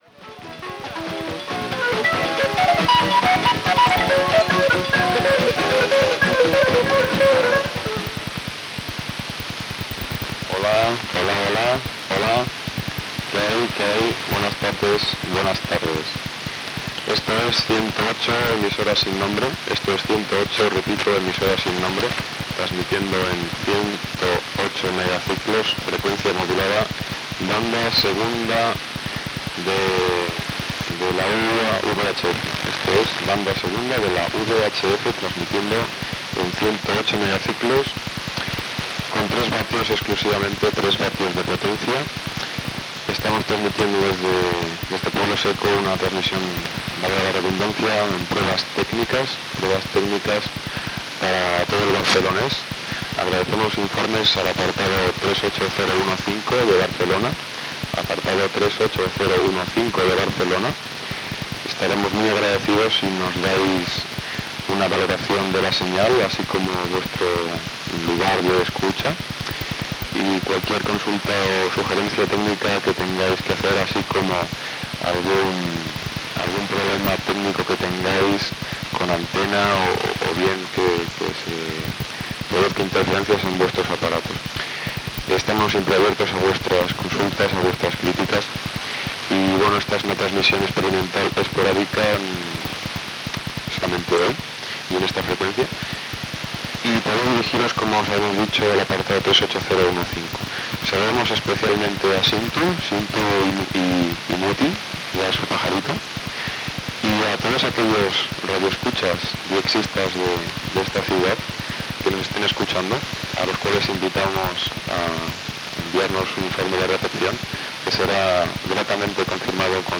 Identificació, demanda de controls de recepció i tema musical.
FM